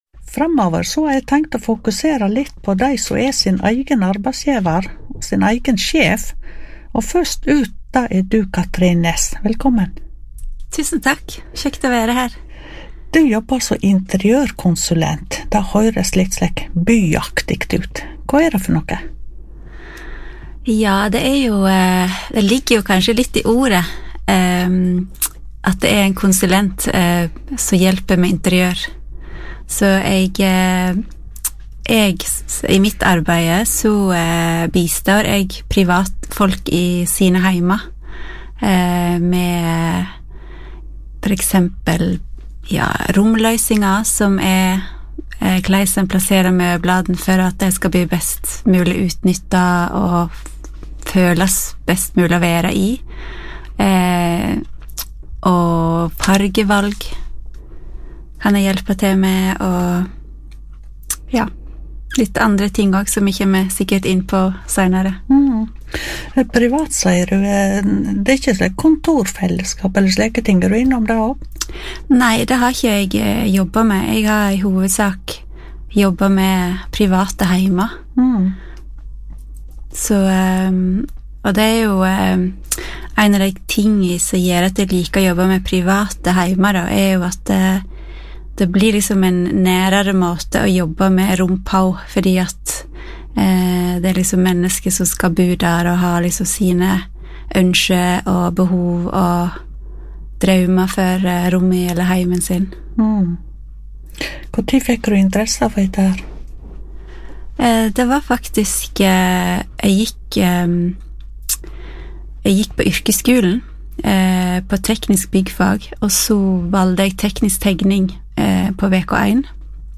Intervju med radio luster